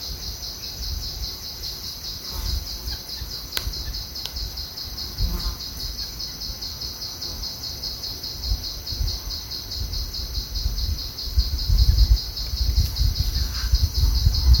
Arapasú (Dendrocincla turdina)
Nombre en inglés: Plain-winged Woodcreeper
Localidad o área protegida: Parque Nacional Iguazú
Condición: Silvestre
Certeza: Vocalización Grabada